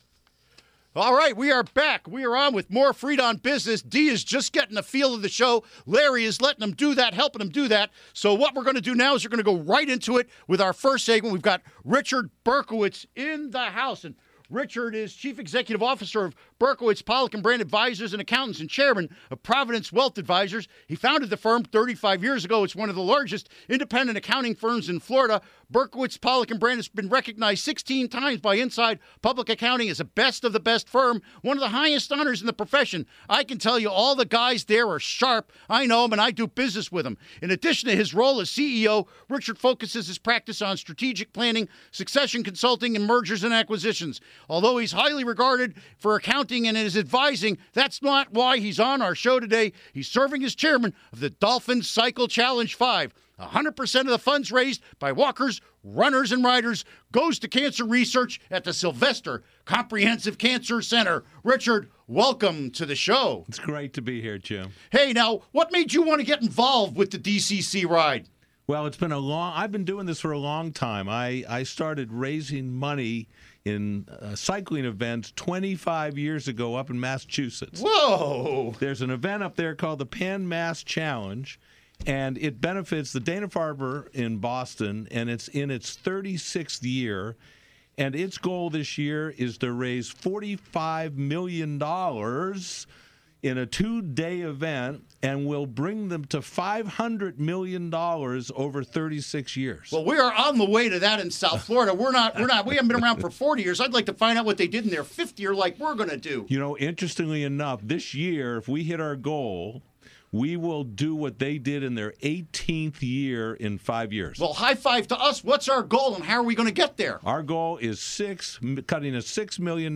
Interview Segment Episode 299: 01-15-15 (To download, right-click this link and select “Save Link As”.)